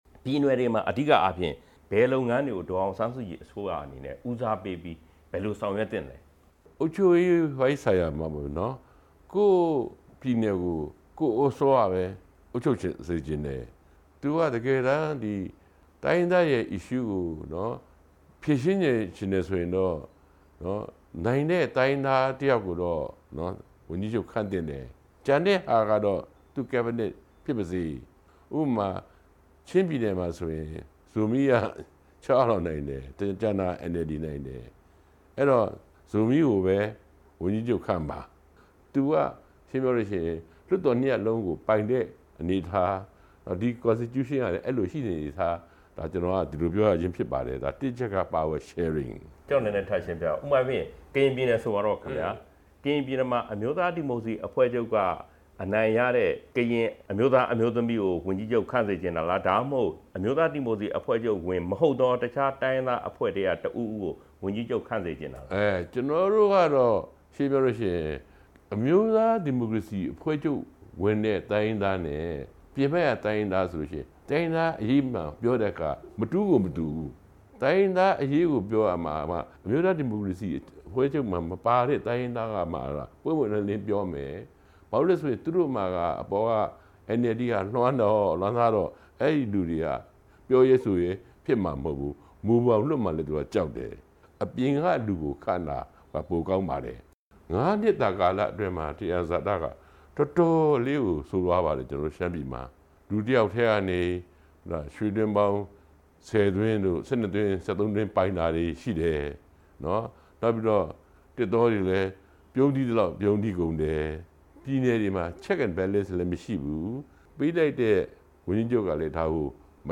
SNLD ဥက္ကဌ ဦးခွန်ထွန်းဦးနဲ့ မေးမြန်းချက် အပိုင်း (၂)